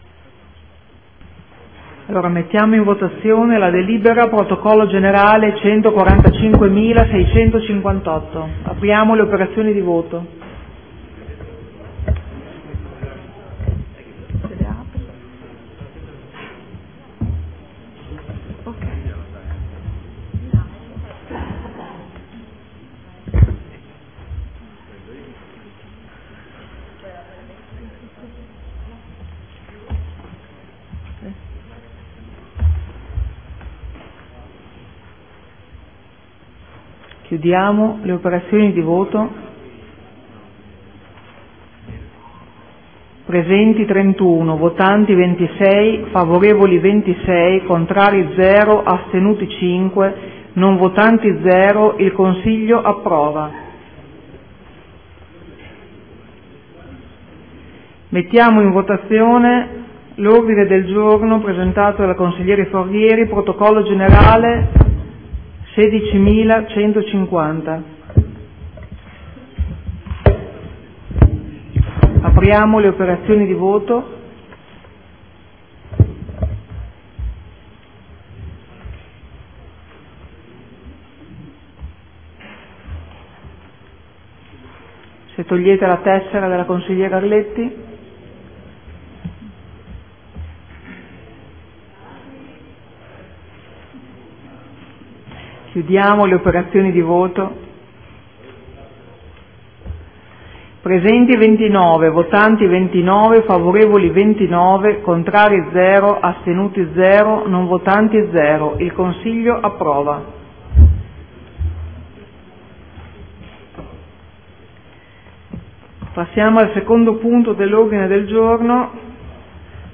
Presidente — Sito Audio Consiglio Comunale
Seduta del 05/02/2015 Mette ai voti. Definizione degli indirizzi per la nomina e la designazione dei rappresentanti nel Comune presso Enti, Aziende, Istituzioni e Società partecipate.